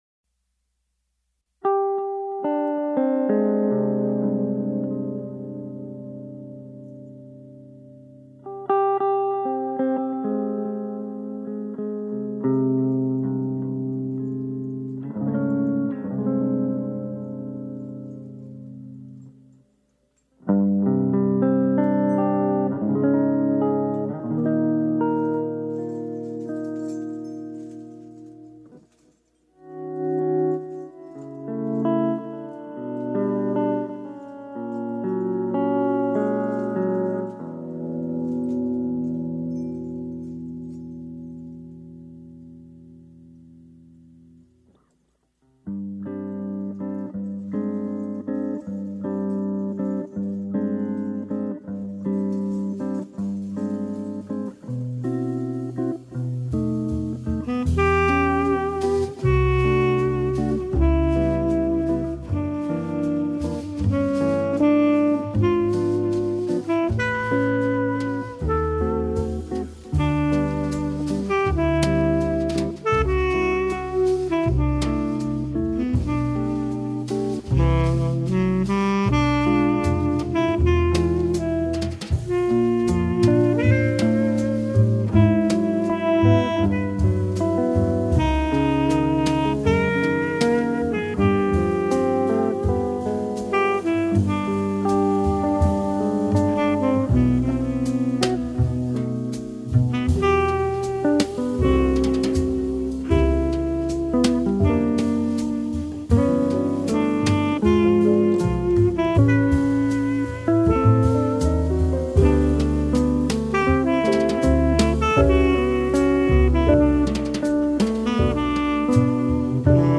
tenor saxophone
guitar
double bass
drums
piano